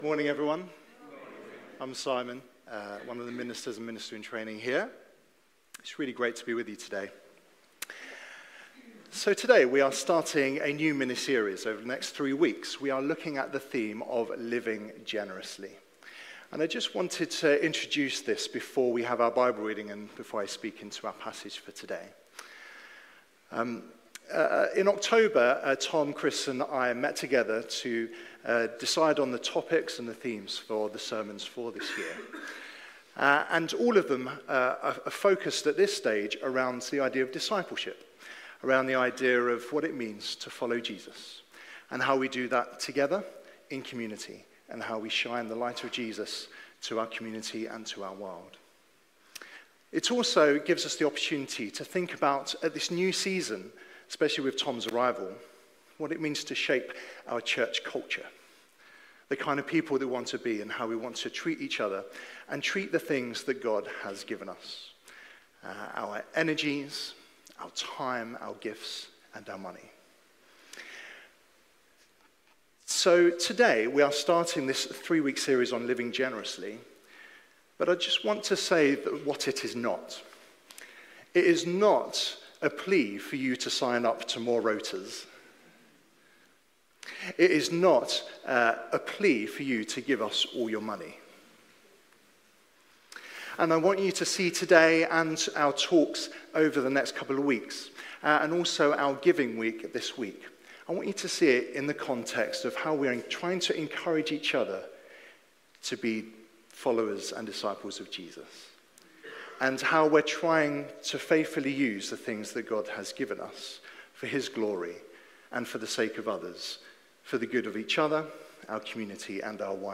Live stream Passage: Mark 10:35-45 Service Type: Sunday Morning Download Files Notes « What have we learnt?